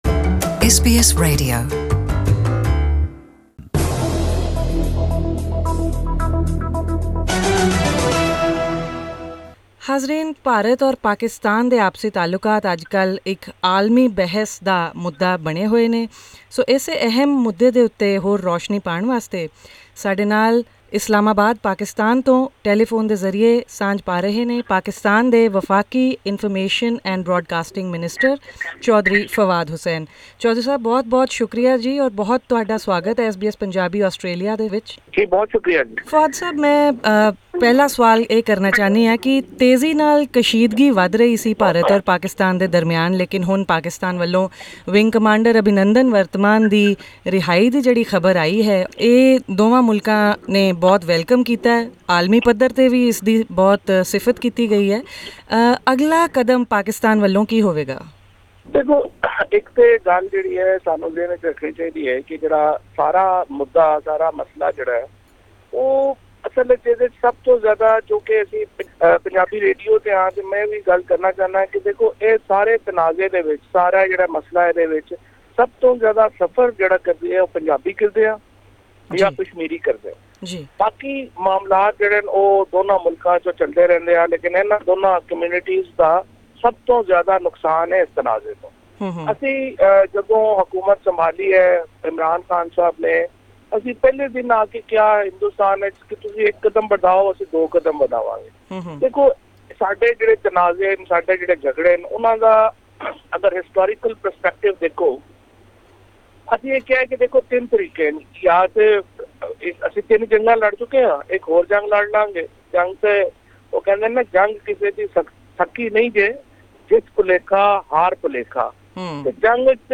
In an exclusive interview with SBS Punjabi , Chaudhary Fawad Hussain, said there are only three options in front of the two countries.